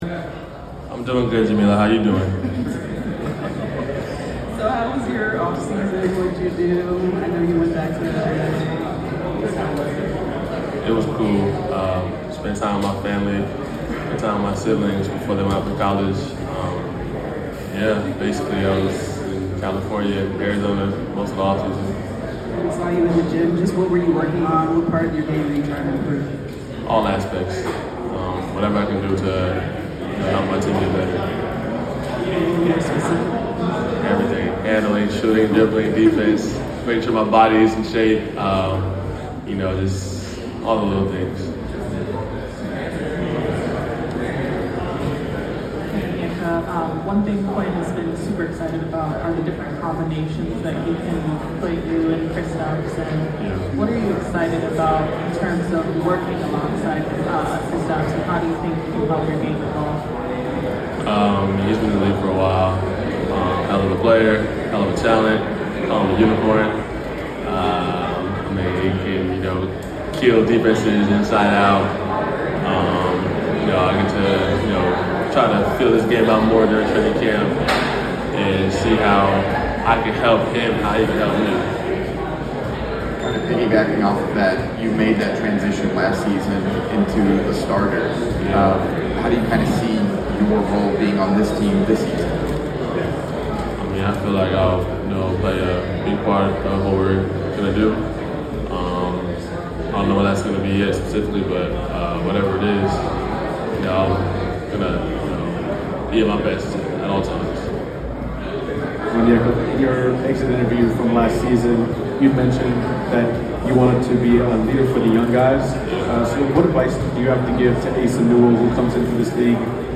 Atlanta Hawks Forward Onyeka Okongwu 2025 Media Day Press Conference at PC&E.